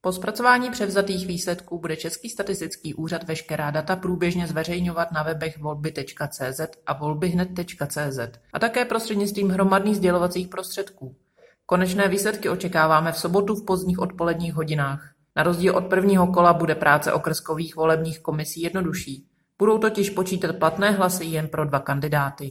Vyjádření místopředsedkyně ČSÚ Evy Krumpové, soubor ve formátu MP3, 881.29 kB